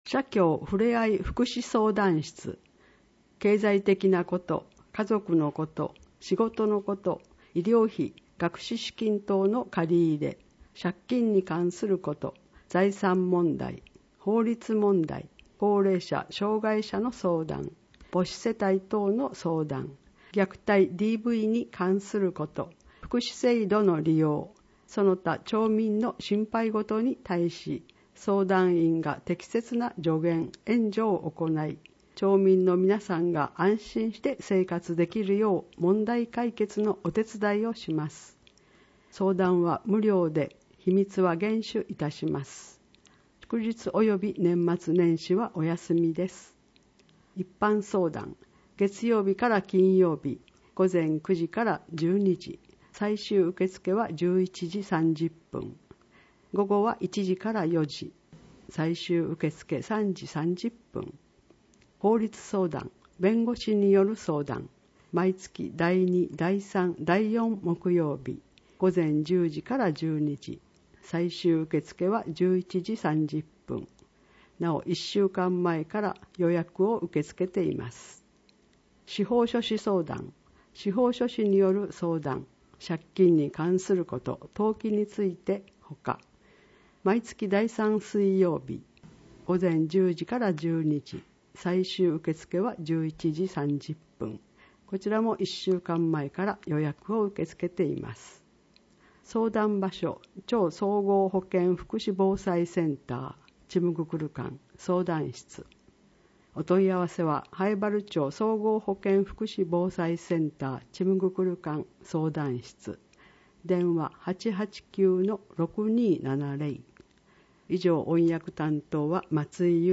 以下は音訳ファイルです